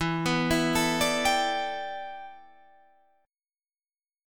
Em11 chord